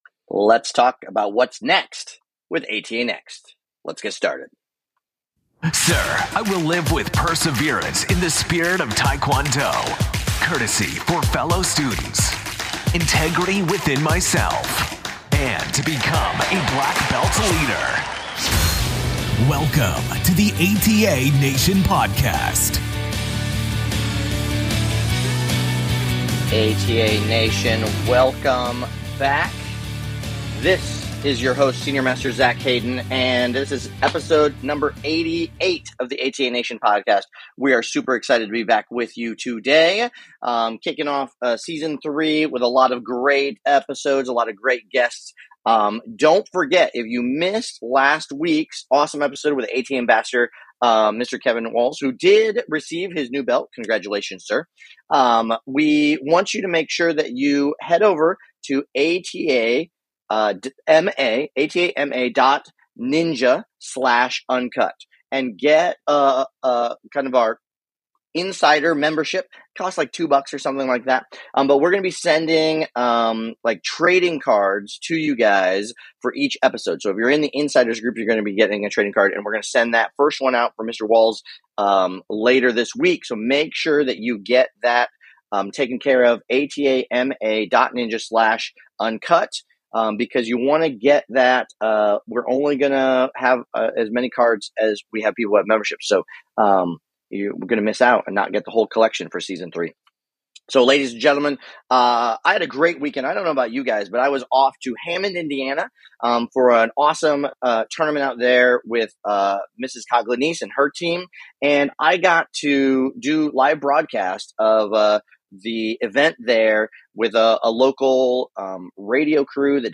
Today we bring you an interview